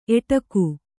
♪ eṭaku